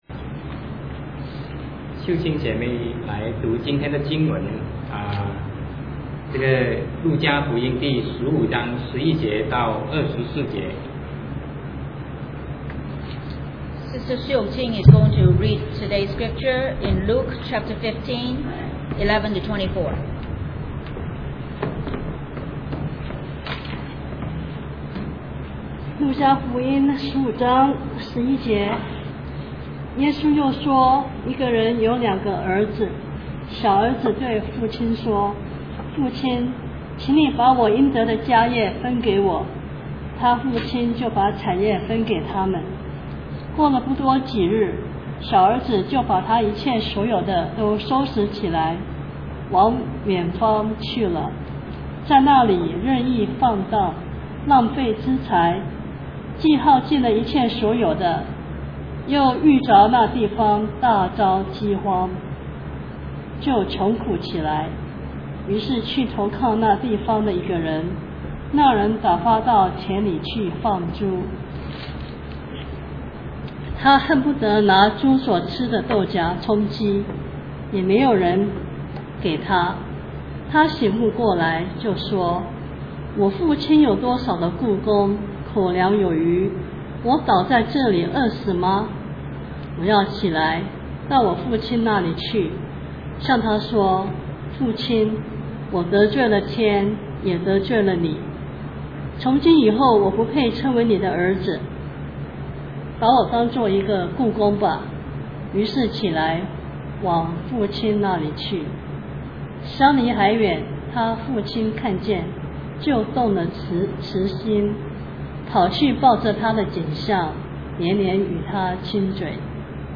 Sermon 2008-06-15 Love of the Heavenly Father